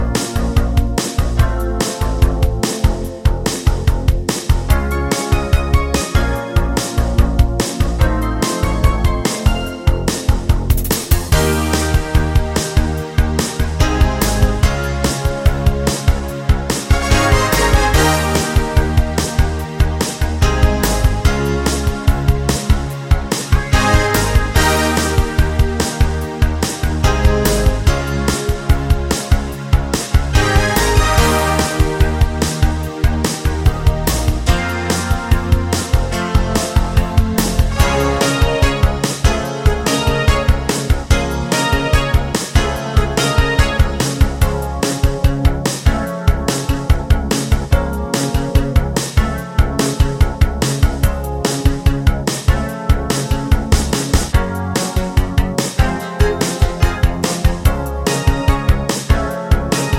Minus All Guitars Pop (1980s) 3:42 Buy £1.50